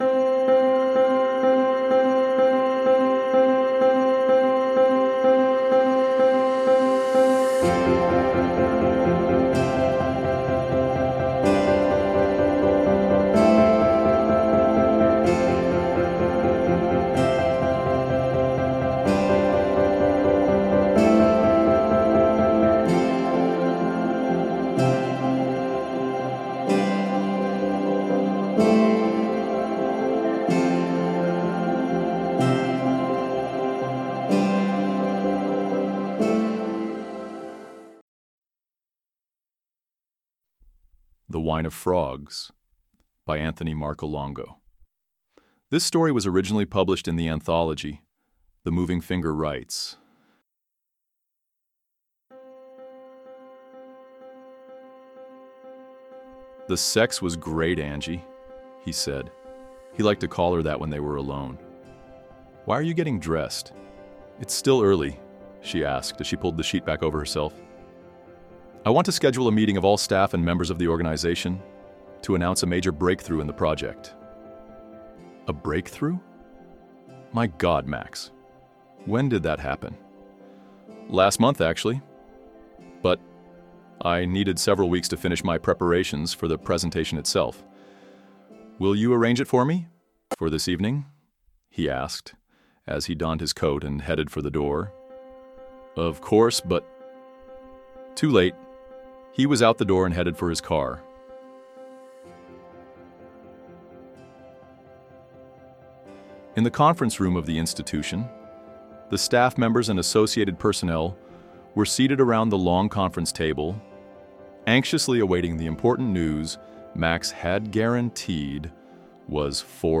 Original Audio Story
Wine-of-Frogs-audio-and-music-FINAL.mp3